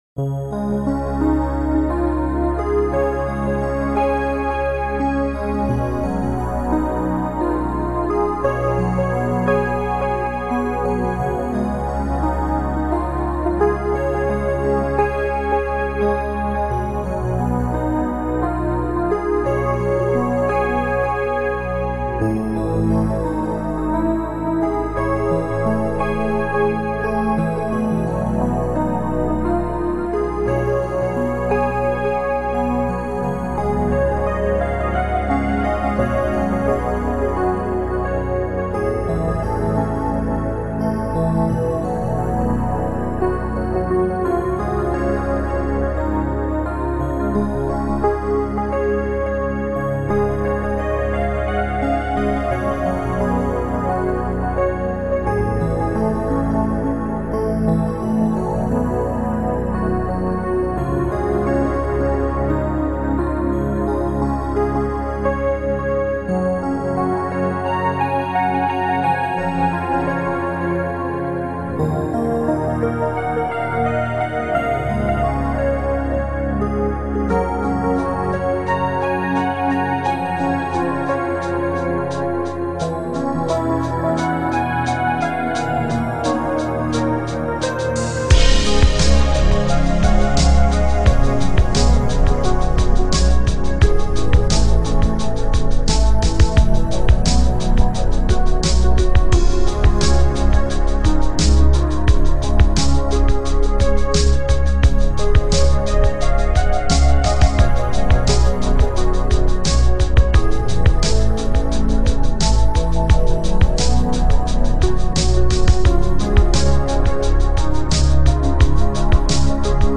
Calm Slow Mo